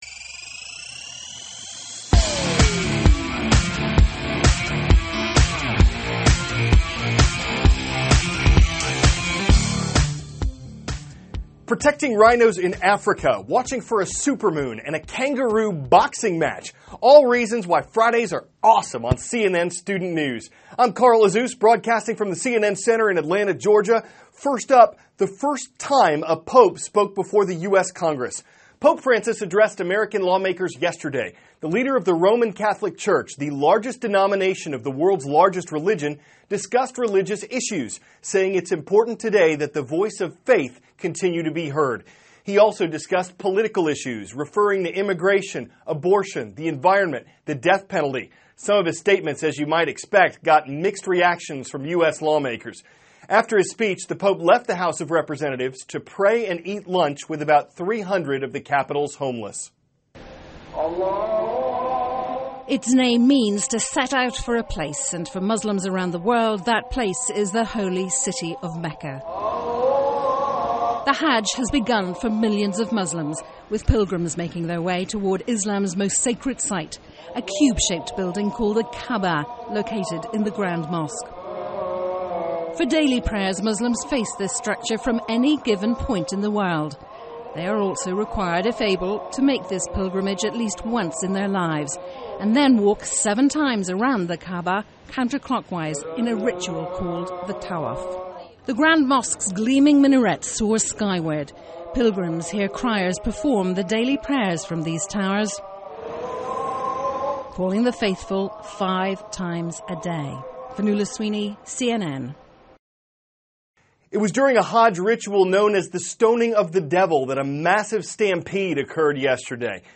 *** CARL AZUZ, cnn STUDENT NEWS ANCHOR: Protecting rhinos in Africa, watching for a supermoon, and a kangaroo boxing match, all reasons why Fridays are awesome on cnn STUDENT NEWS.